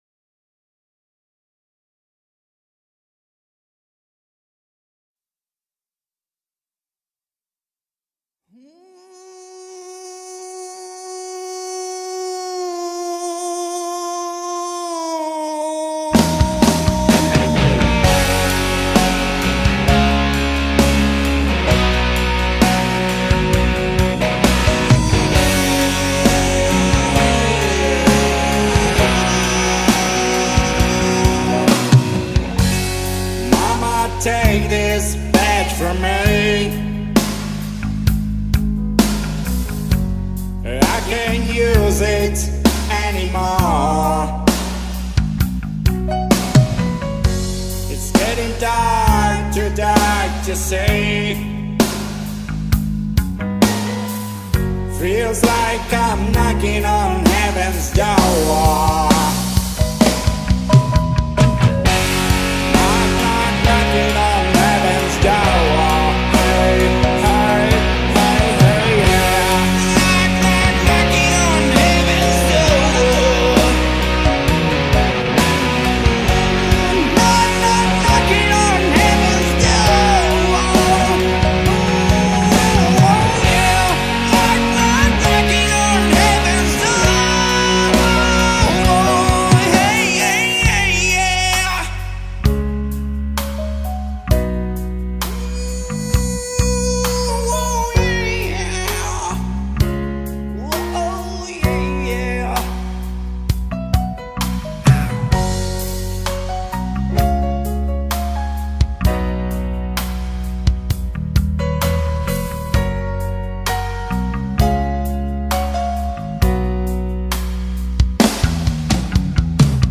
Accords G D Am Am G D C C